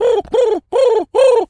Animal_Impersonations
pigeon_call_sequence_01.wav